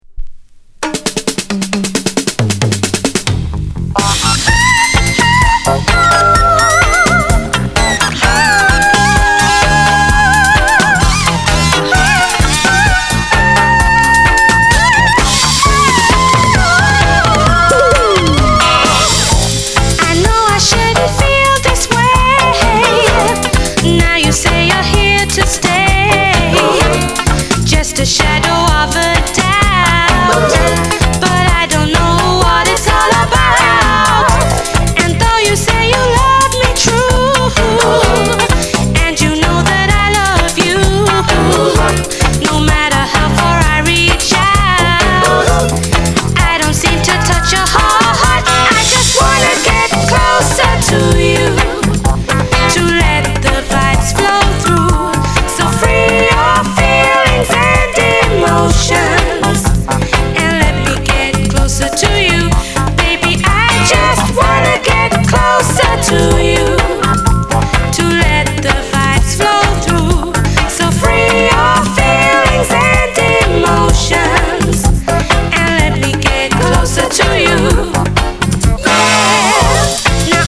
Genre: Reggae/Lovers Rock